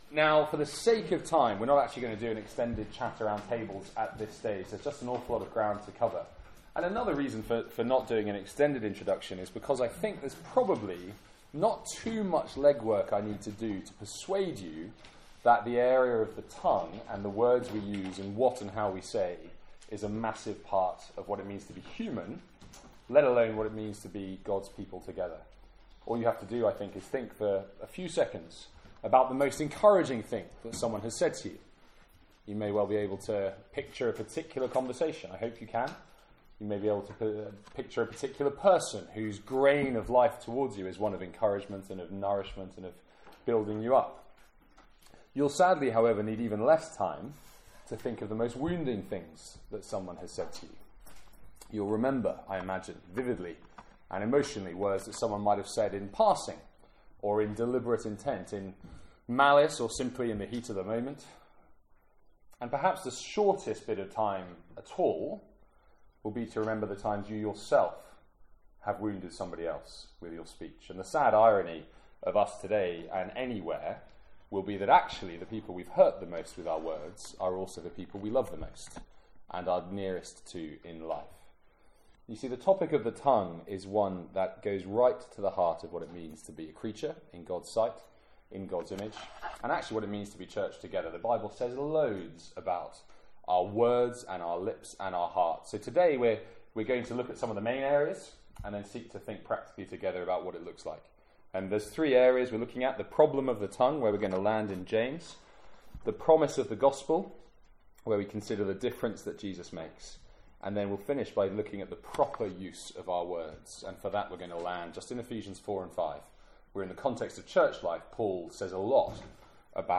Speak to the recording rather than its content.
From our student lunch on 6th November 2016.